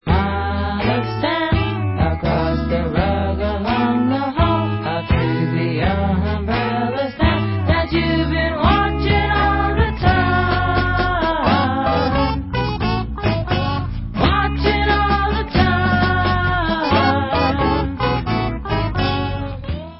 sledovat novinky v oddělení Rock